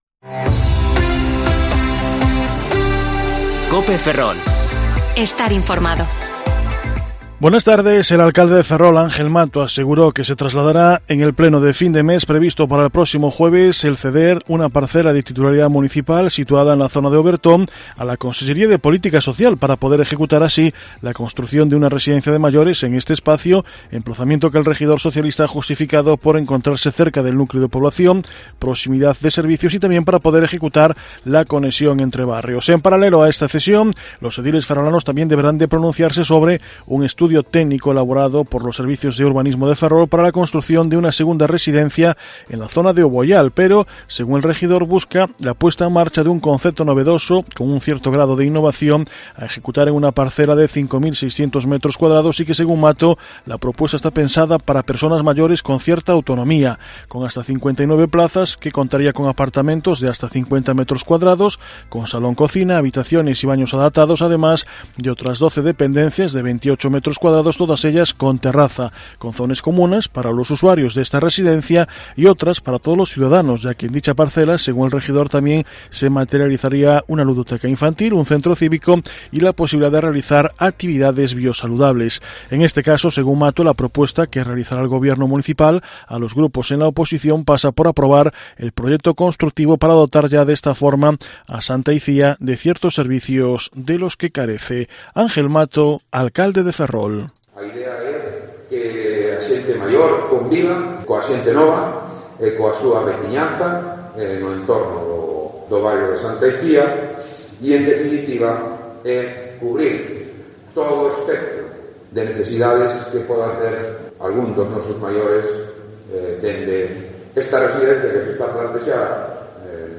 Informativo Mediodía COPE Ferrol 24/5/2021 (De 14,20 a 14,30 horas)